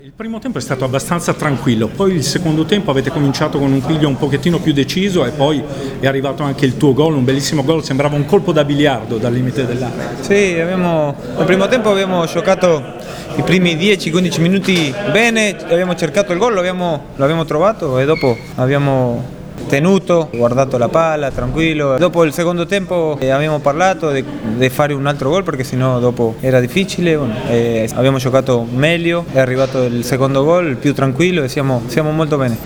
Le interviste post-partita: